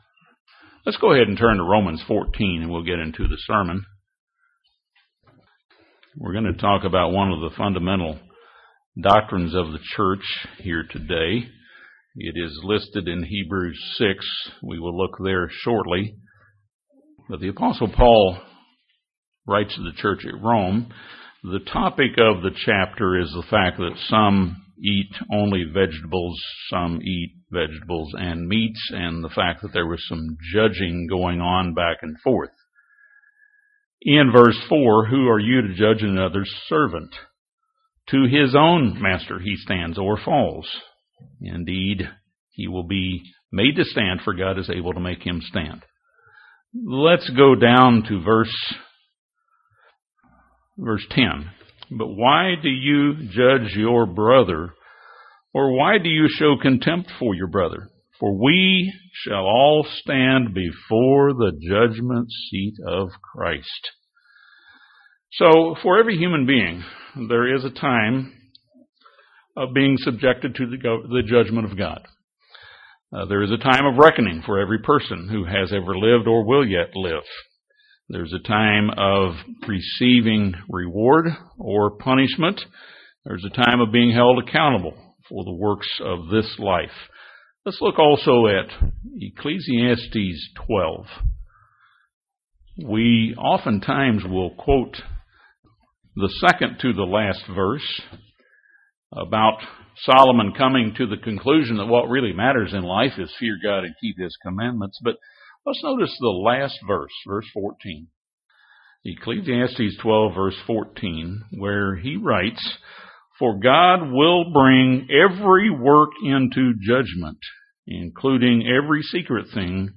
This sermon looks at the topic of judgment upon the righteous angels, Satan and his demons, and humankind.